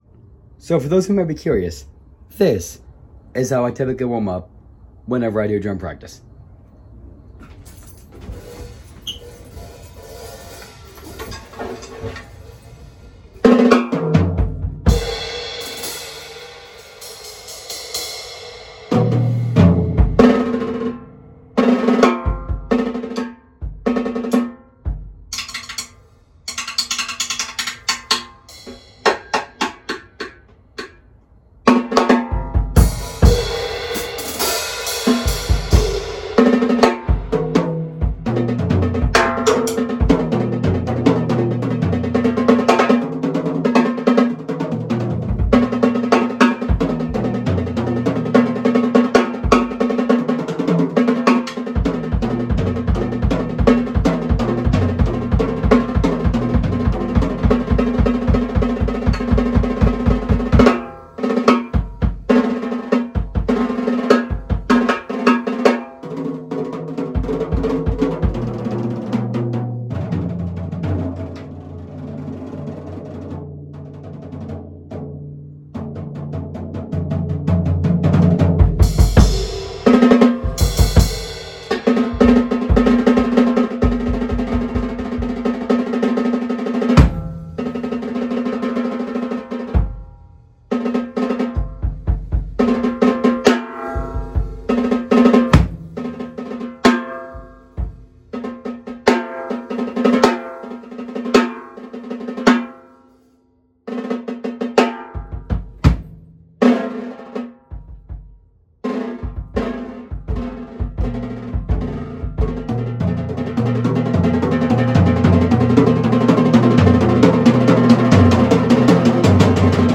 Here's some audio of me just warming up behind the drum kit.